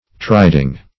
triding - definition of triding - synonyms, pronunciation, spelling from Free Dictionary Search Result for " triding" : The Collaborative International Dictionary of English v.0.48: Triding \Tri"ding\, n. A riding.